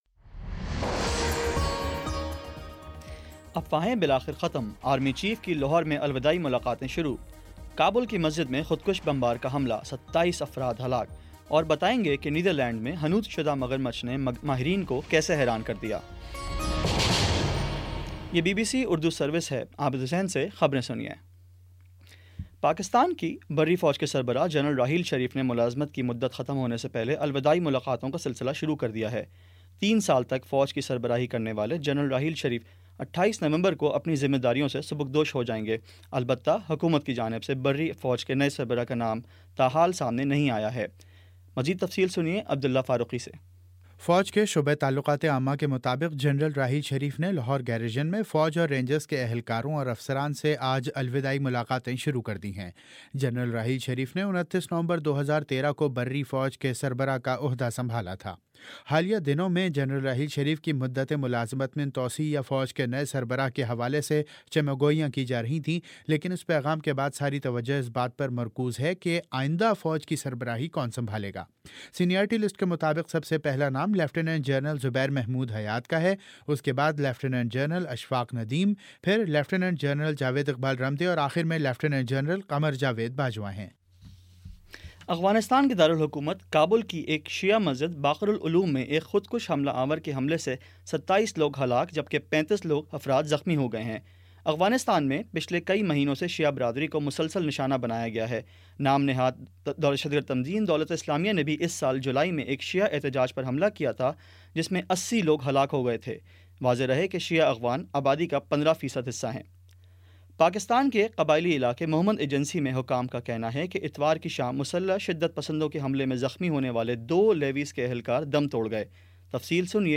نومبر 21 : شام پانچ بجے کا نیوز بُلیٹن